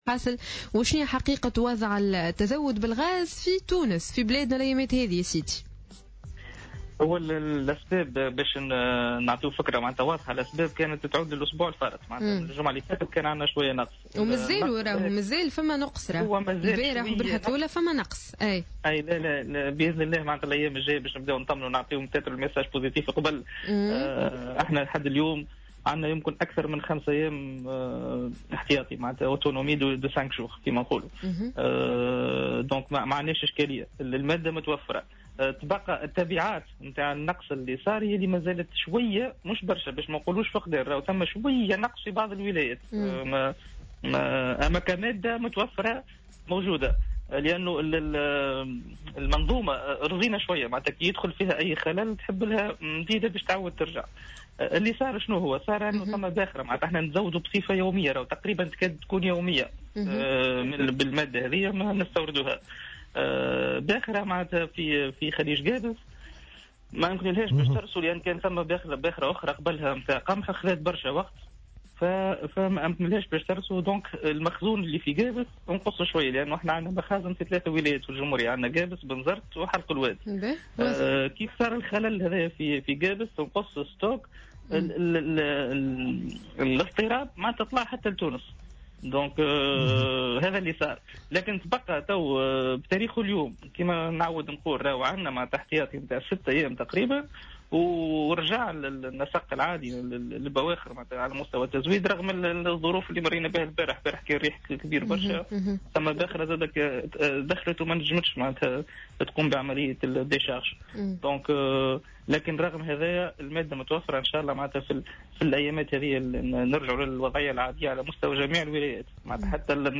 أكد مدير التجارة الداخلية بوزارة التجارة خالد بن عبد الله في مداخلة له على جوهرة "اف ام" اليوم الجمعة 6 مارس 2015 أن النقص الذي تم تسجيله في الفترة الماضية في التزود بمادة الغاز تم تداركه موضحا أن تم توفير احتياطي 5 أيام من هذه المادة.